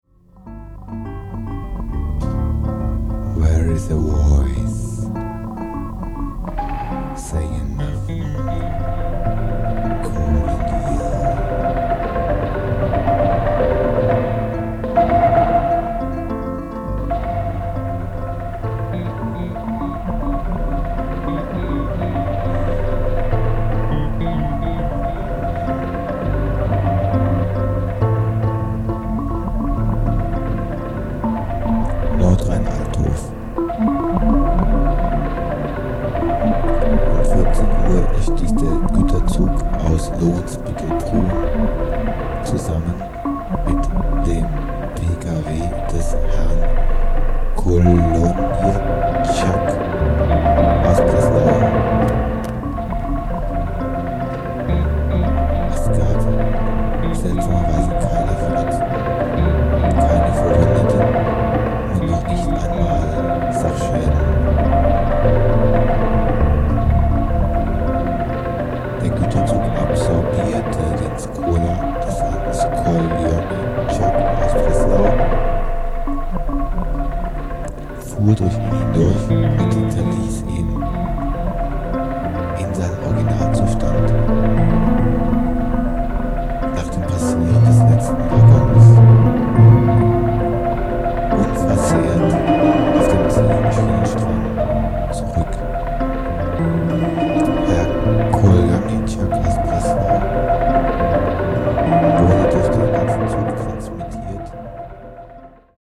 ミニマル　サウンドスケープ　宅録